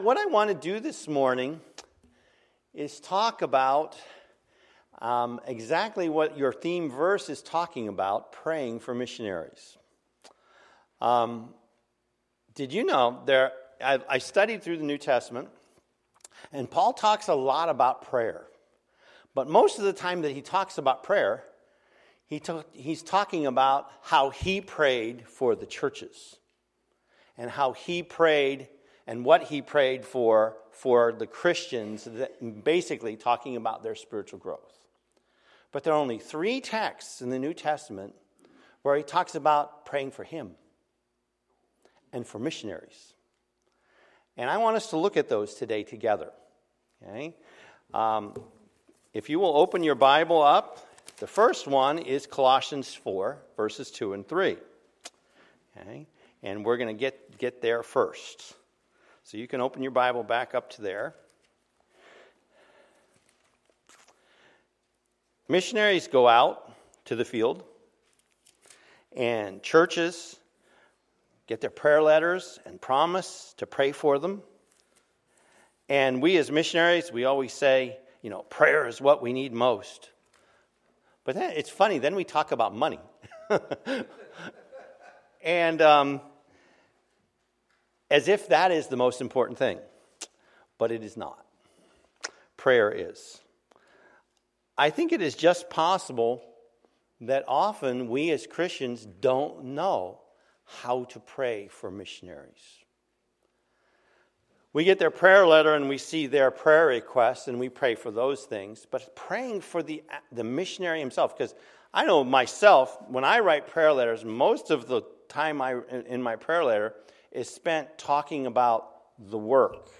Sunday, September 25, 2016 – 2016 Missions Conference – Sunday School Session